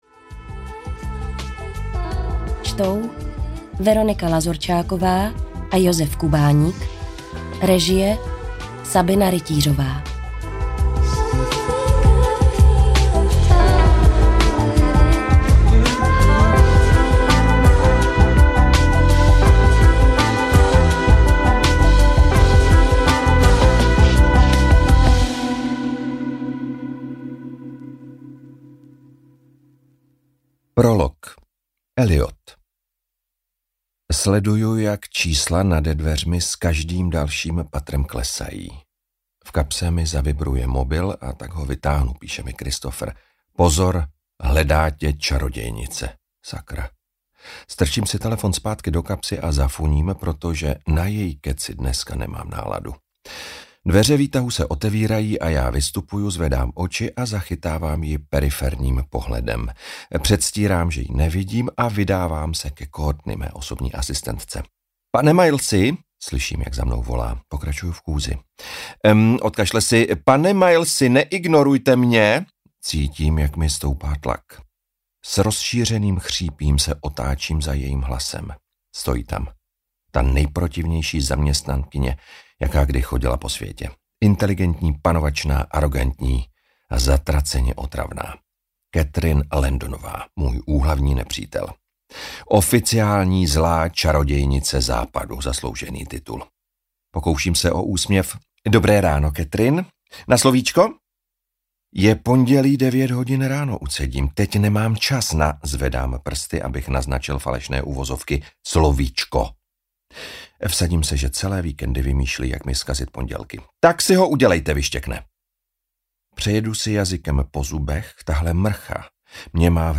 Bez zábran audiokniha
Ukázka z knihy
bez-zabran-audiokniha